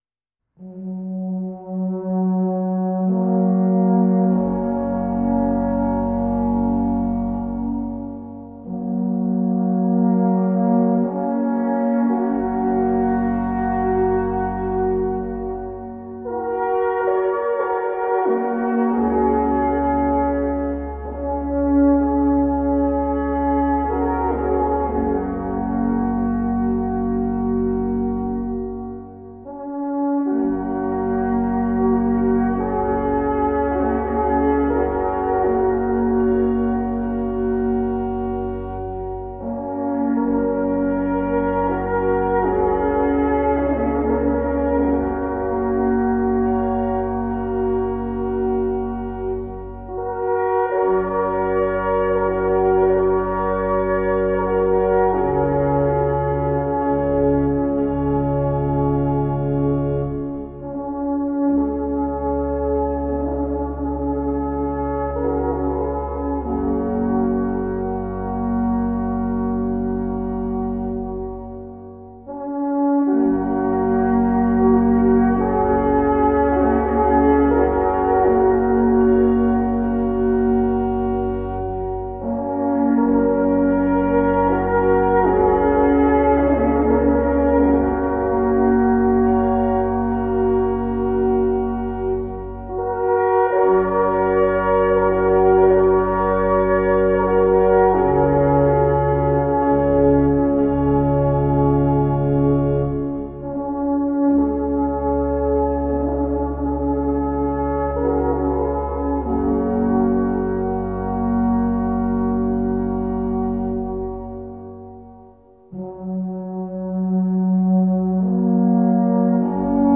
Traditionelle und moderne Alphornmusik